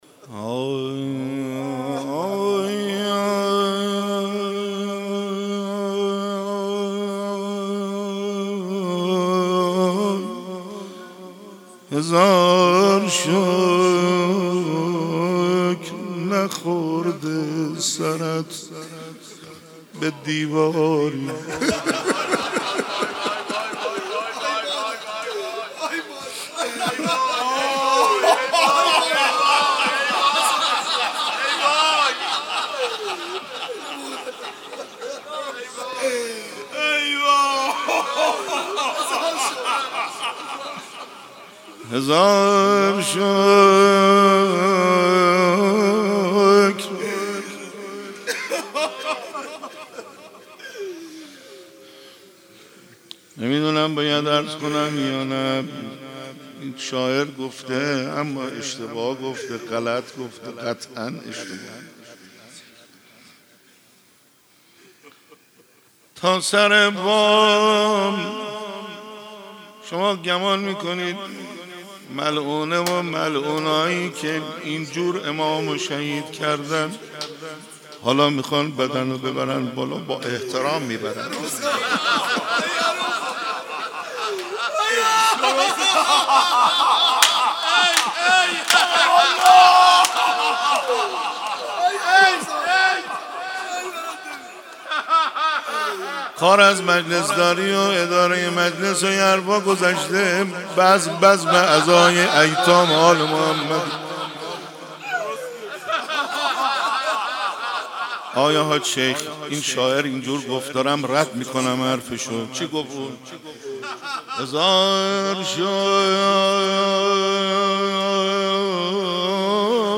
در مجلس حسینیه جلوه
مداحی به سبک روضه اجرا شده است.